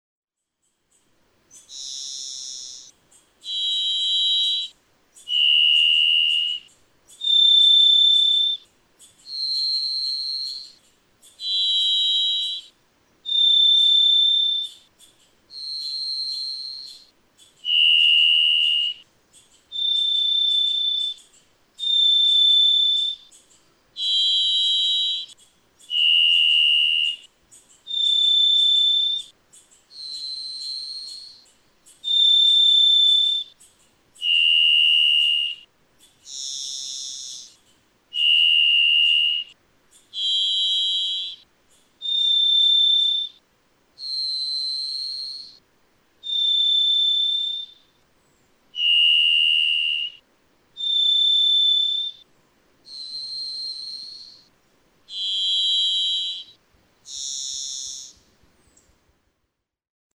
Dissonance—varied thrush
♫654. With most of the silent spaces removed from between songs, the dissonance is better heard, as is the contrast from one song to the next. June 9, 2009. Powell, Idaho. (1:00)
654_Varied_Thrush.mp3